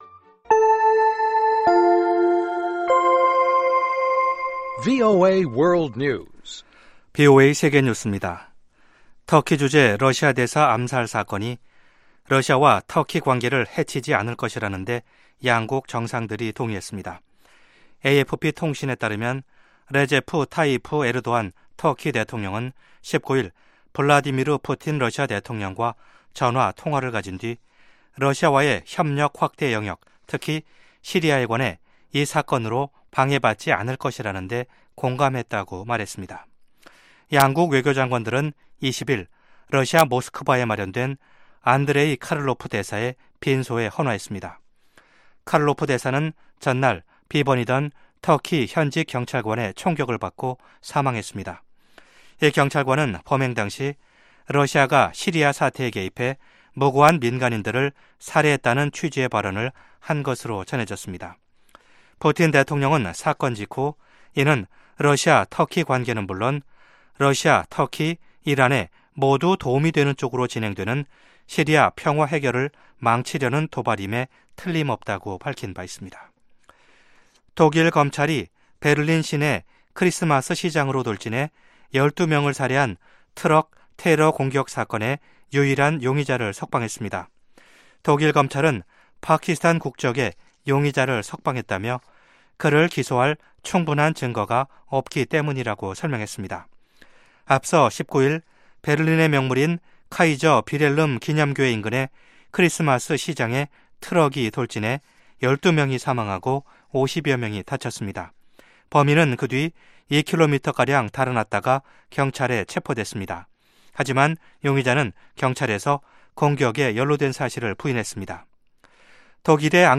VOA 한국어 방송의 아침 뉴스 프로그램 입니다.